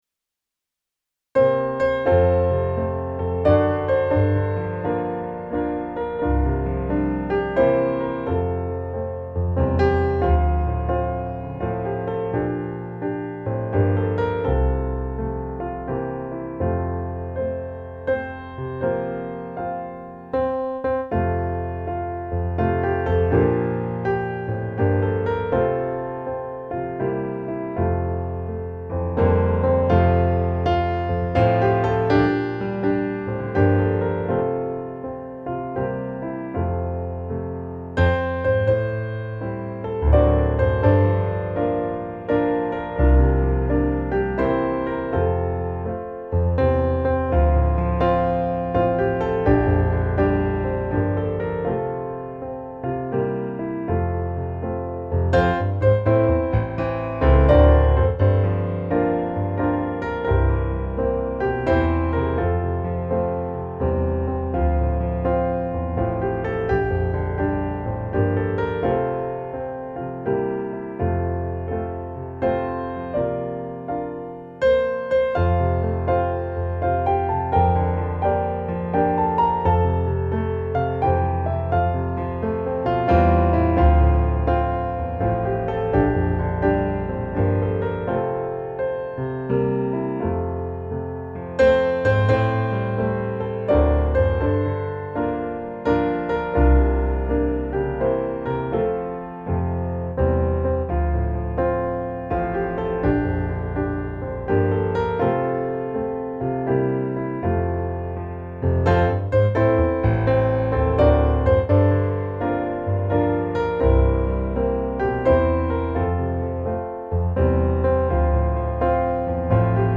Namnet Jesus vill jag sjunga - musikbakgrund